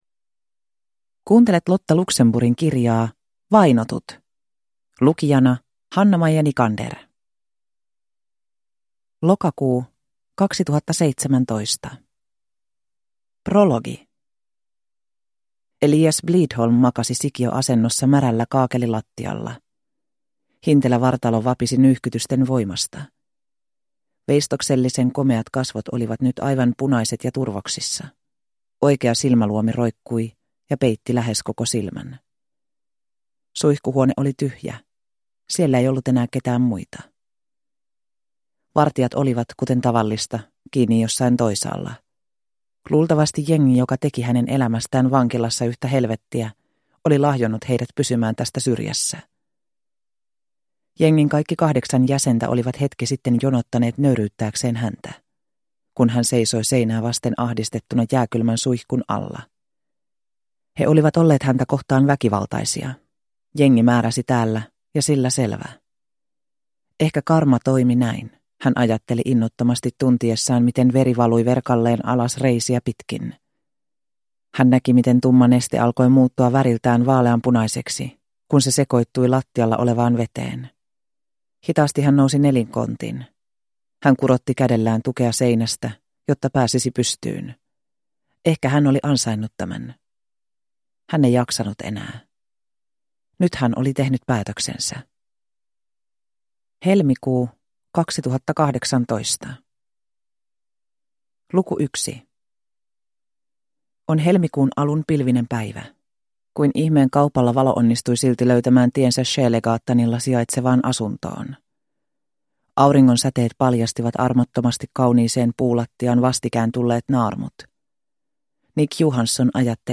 Vainotut – Ljudbok – Laddas ner